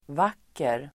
Uttal: [v'ak:er]